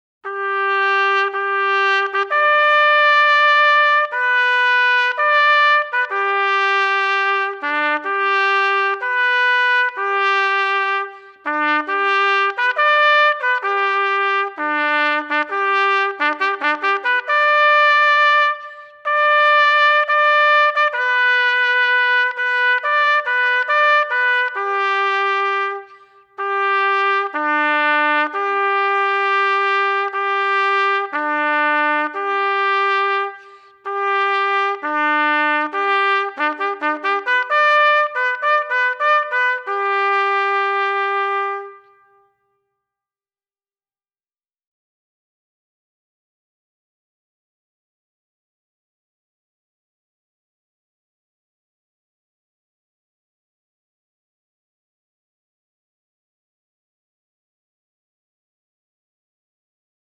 Listen: To the Colors - YouTube Download MP3: Download "To the Colors" 🌆 Evening Flag Lowering - Retreat "Retreat" is the traditional bugle call used to signal the end of the duty day and the beginning of the evening flag-lowering ceremony.